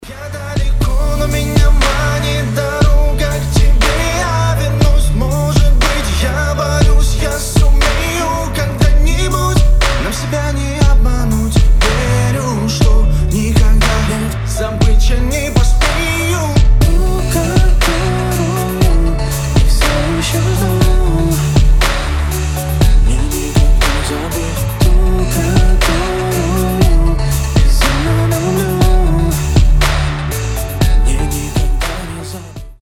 • Качество: 320, Stereo
поп
мужской вокал
мелодичные
романтичные
лиричные